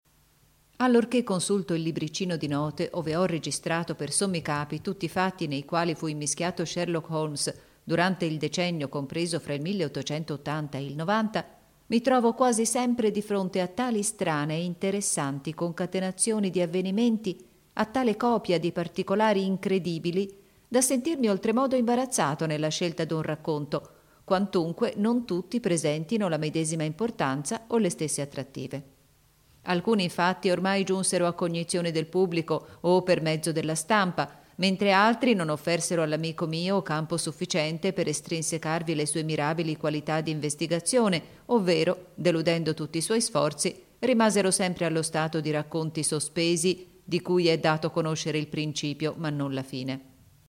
Sprecherin italienisch.
Kein Dialekt
Sprechprobe: Industrie (Muttersprache):
female italian voice over artist.